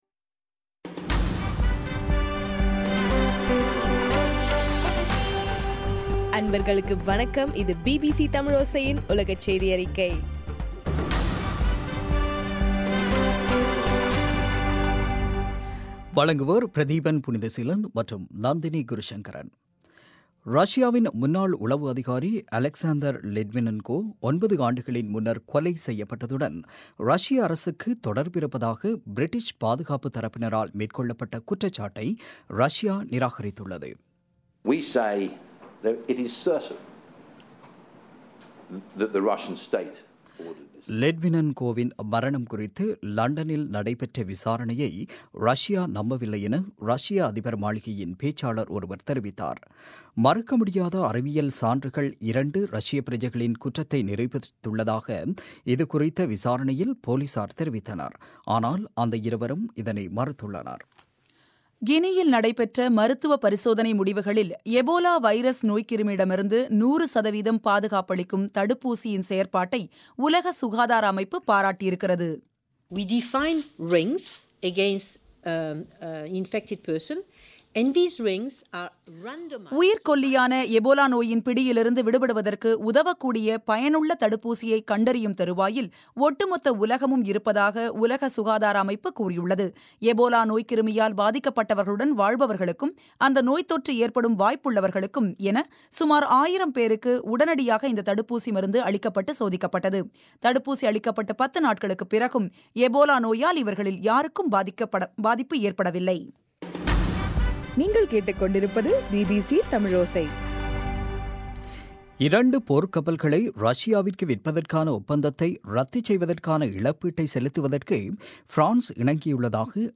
இன்றைய உலகச் செய்தியறிக்கை - ஜூலை 31